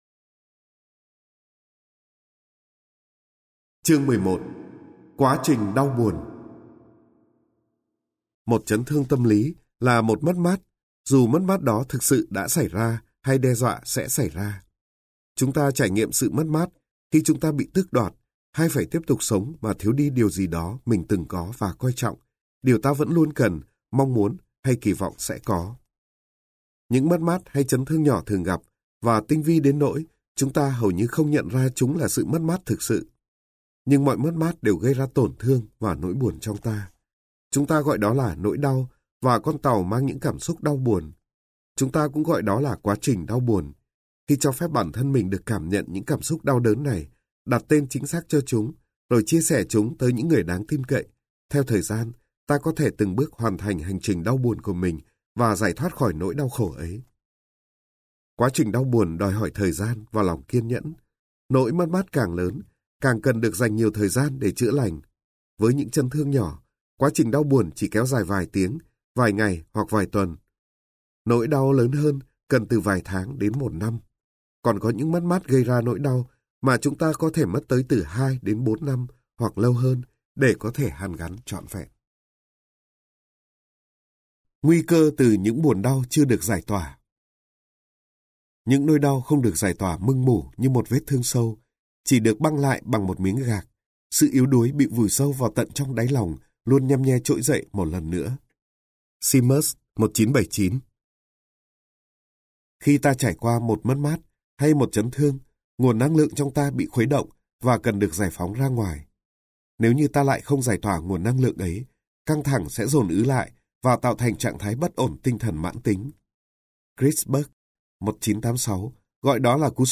Sách nói Chữa lành đứa trẻ bên trong bạn - Hạt Giống Tâm Hồn - Sách Nói Online Hay
Người đọc: Nhiều người đọc